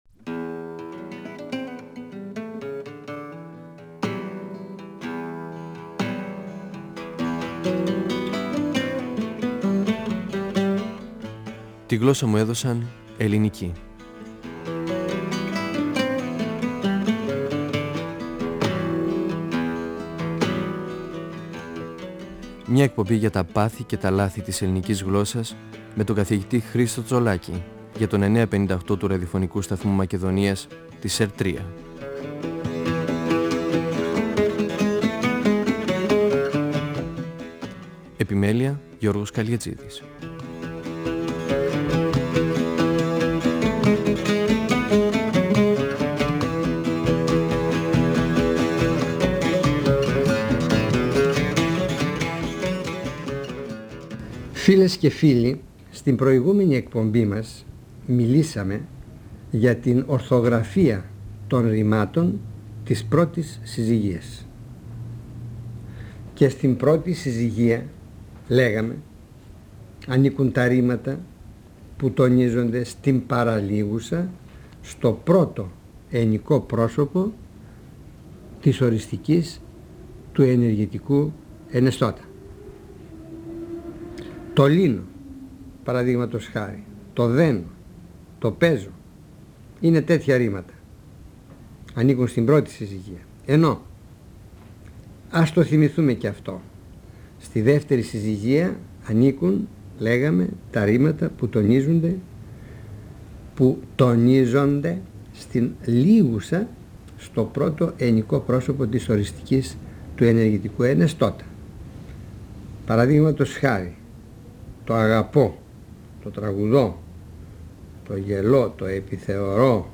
Ο γλωσσολόγος Χρίστος Τσολάκης (1935-2012) μιλά για την ορθογραφία των ρημάτων της πρώτης συζυγίας που έχουν λάμδα στην κατάληξή τους, για το πώς δημιουργήθηκαν τα δύο λάμδα και πώς αποσιωπήθηκε το δεύτερο από αυτά κατά την προφορά τους. Μιλά για την ορθογραφία των ρημάτων που έχουν σίγμα στην κατάληξή τους ή ταυ, και για τις εξαιρέσεις.